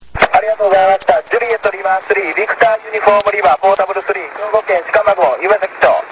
ちなみに移動地は清水市駒越海岸。
SP　SAMPLE-1　５エレでの受信　(REAL AUDIO)
SAMPLE-1では５エレの方が若干強く聞こえます。しかし、ＤＰでも十分に強く聞こえます。